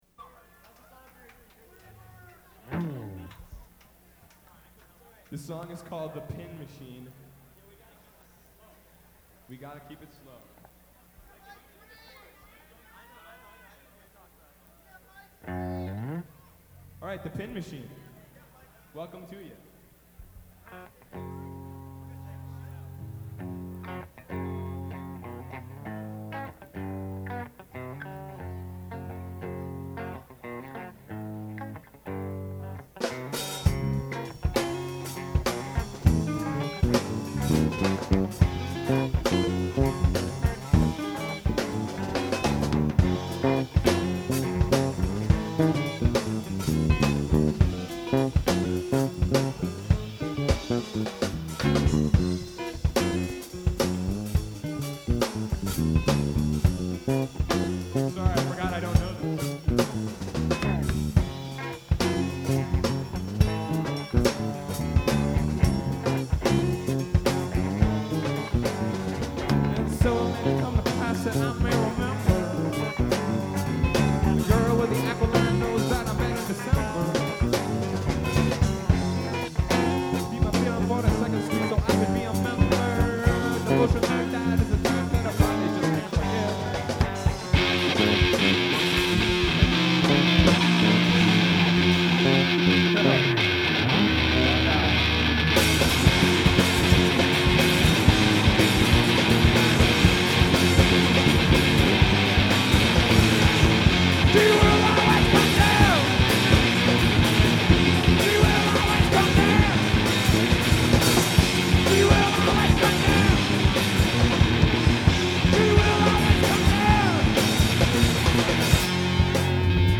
Bass
Lead Guitar, Vocals
Drums
Live at the Underground (2/9/96) [Entire Show]